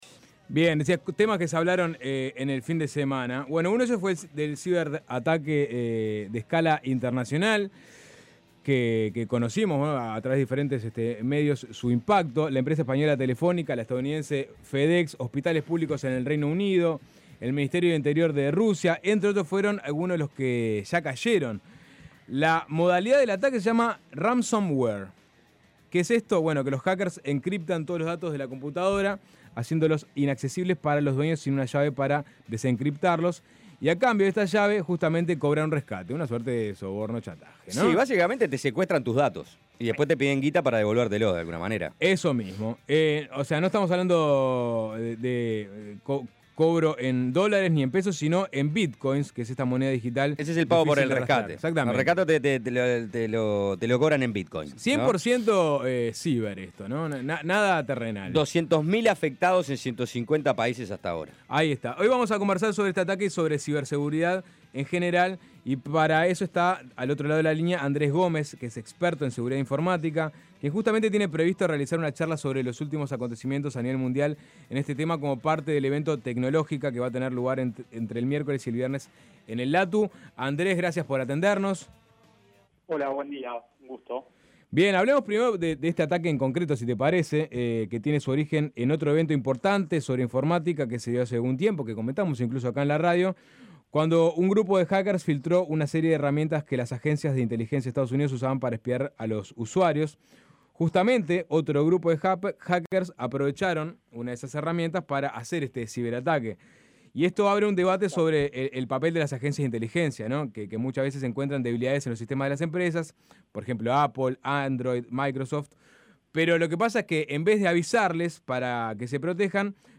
Entrevista en Suena Tremendo ¿Cómo actúa el ciberataque masivo que llegó a nuestro país?